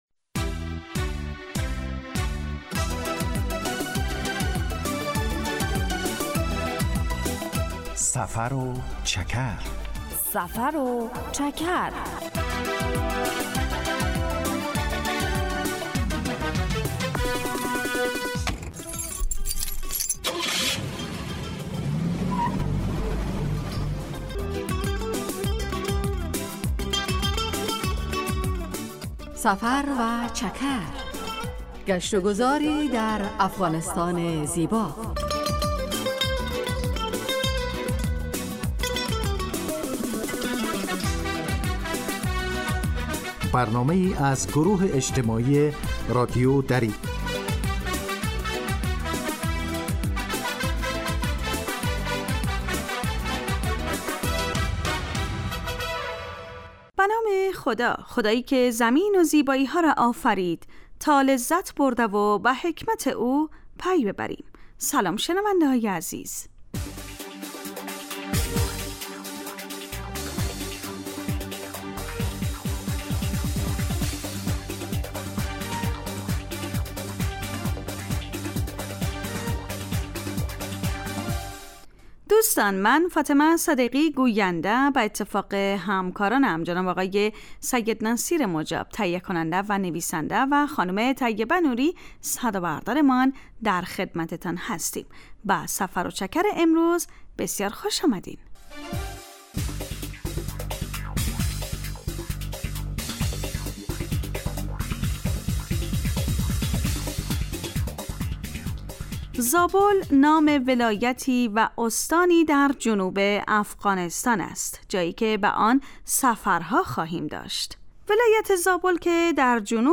سفر و چکر برنامه ای 15 دقیقه از نشرات رادیو دری است که به معرفی ولایات و مناطق مختلف افغانستان می پردازد. در این برنامه مخاطبان با جغرافیای شهری و فرهنگ و آداب و سنن افغانی آشنا می شوند. در سفر و چکر ؛ علاوه بر معلومات مفید، گزارش و گفتگو های جالب و آهنگ های متناسب هم تقدیم می شود.